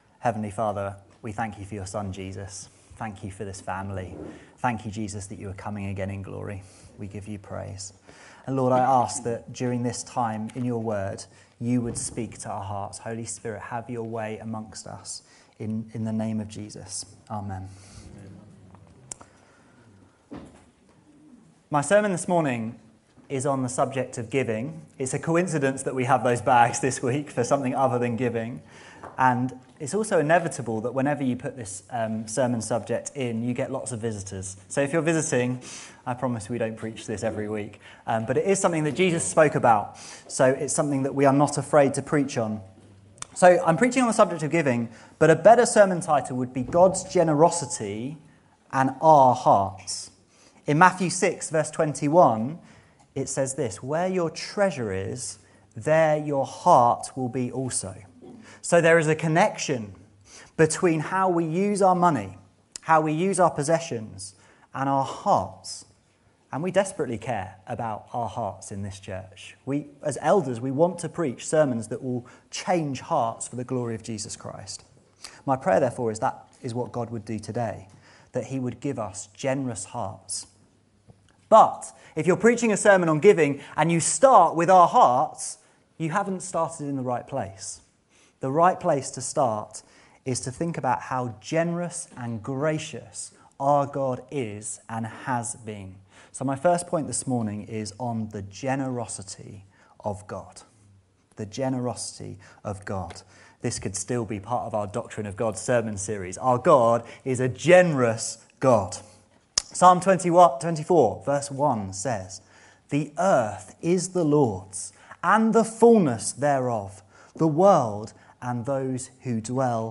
This sermon stirs Christian hearts to live generously for God including with our finances.